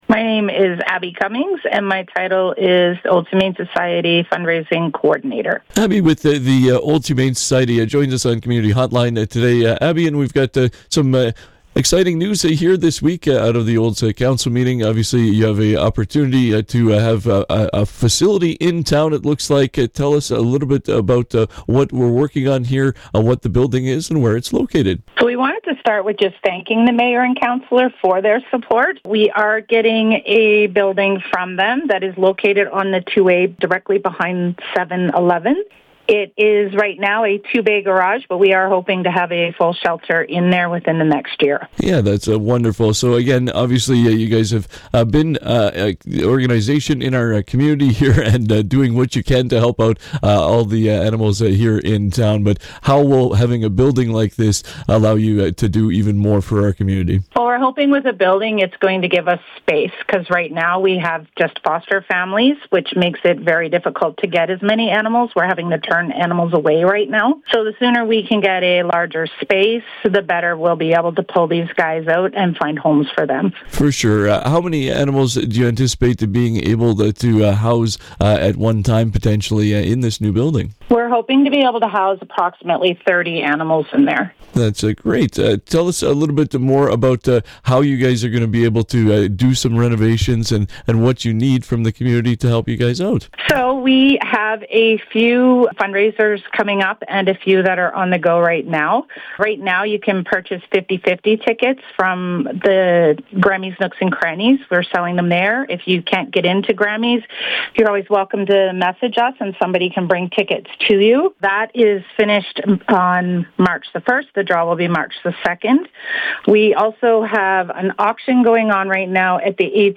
96.5 The Ranch’s Community Hotline conversation